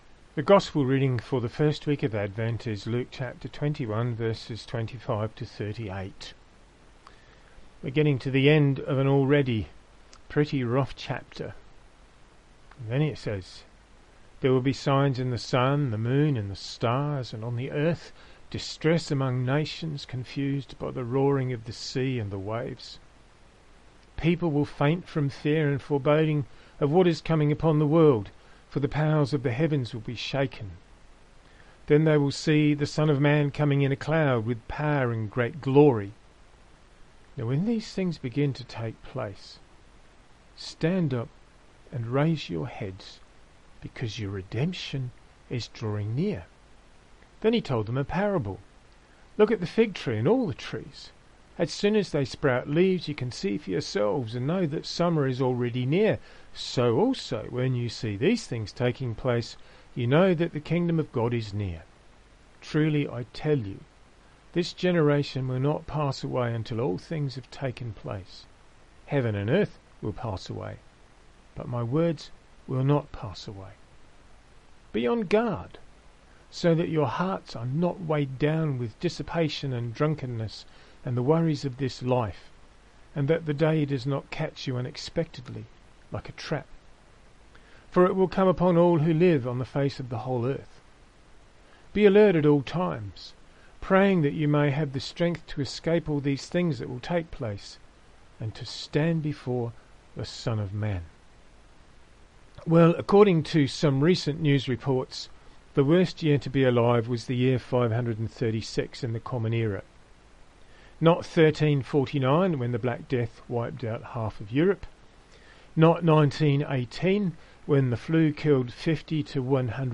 Sermon According to some recent news reports, the worst year to be alive was the year 536.